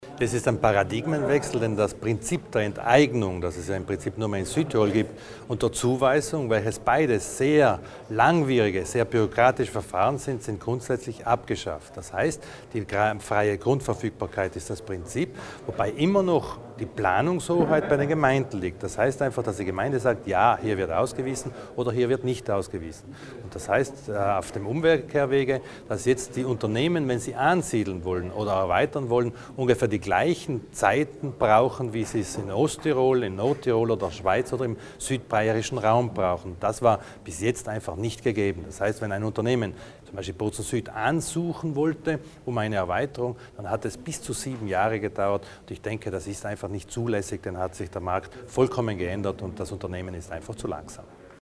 Landesrat Widmann zu den Neuheiten in Sachen Gewerbegebiete
Kein Reförmchen, sondern einen regelrechten Paradigmenwechsel stellt die Neuordnung der Gewerbegebiete dar. Darauf hat Landesrat Thomas Widmann heute (18. Juli) bei der Vorstellung der Reform gepocht. Die Abkehr von Enteignung und Zuweisung helfe, Zeit, Geld und Bürokratie zu sparen, und mache Südtirol in Sachen Ansiedlungspolitik wieder konkurrenzfähig.